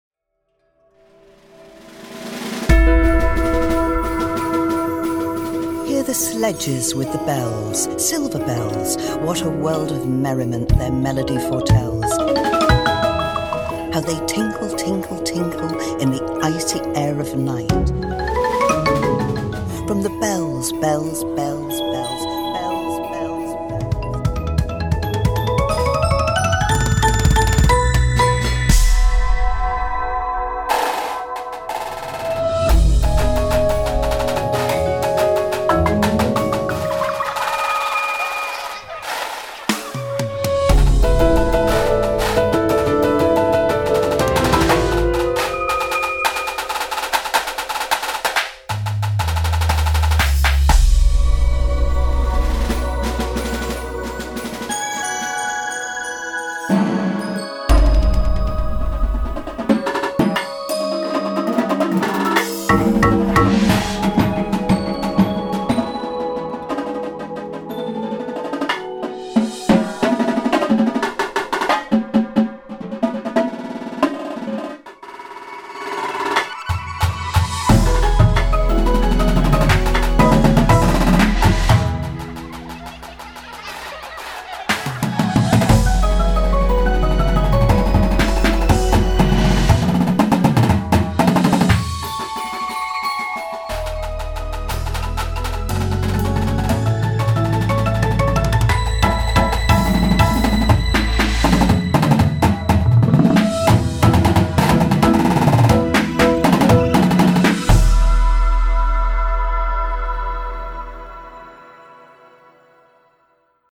Indoor Percussion Shows
Front Ensemble
• 3 Marimbas